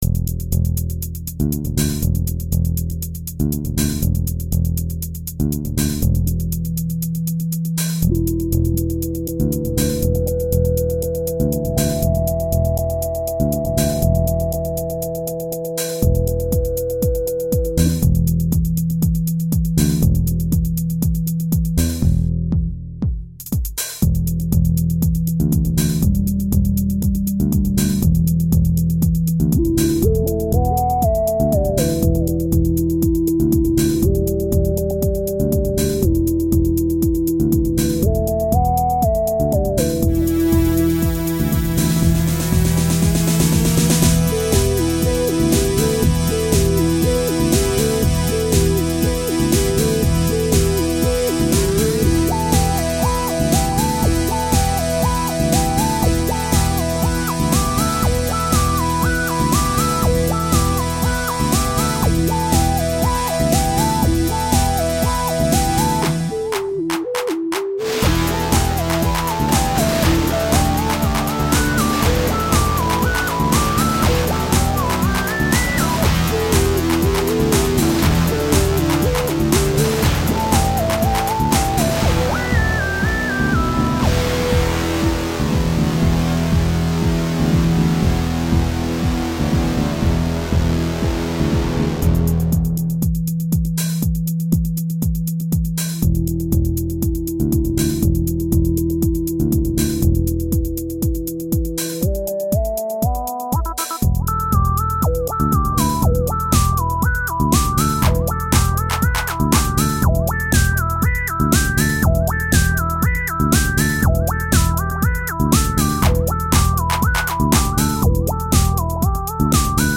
is a heroic electronic rock anthem whose driving rhythm
Tagged as: Electronica, Other